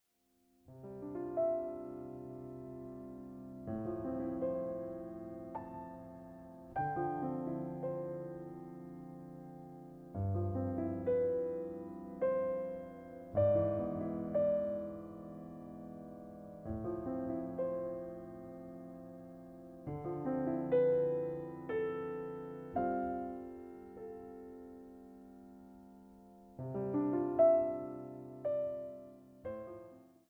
様々な情景、抒情を見せるピアノ・ソロの世界が広がるアルバムとなっています。